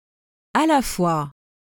🎧 How to pronounce à la fois in French
a la fwa/ — roughly ah lah fwah.